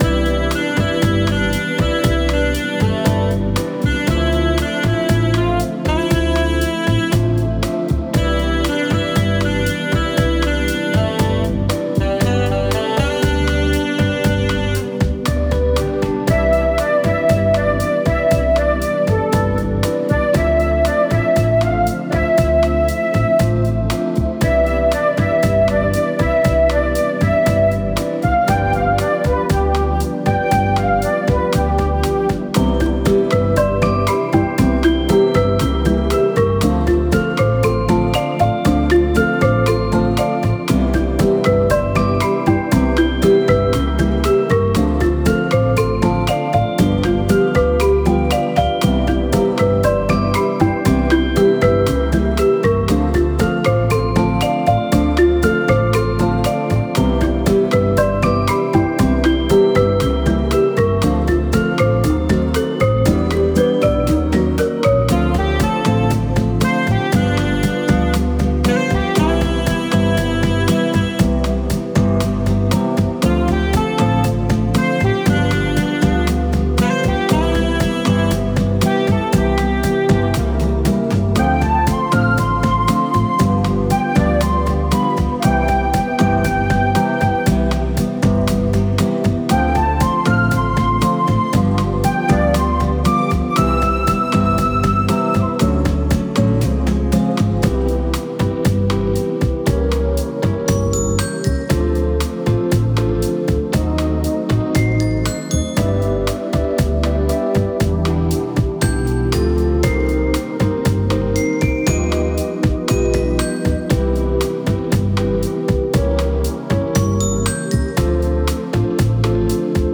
Music for puzzle game.